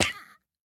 Minecraft Version Minecraft Version 1.21.5 Latest Release | Latest Snapshot 1.21.5 / assets / minecraft / sounds / mob / turtle / baby / hurt1.ogg Compare With Compare With Latest Release | Latest Snapshot
hurt1.ogg